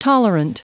Prononciation du mot tolerant en anglais (fichier audio)
Prononciation du mot : tolerant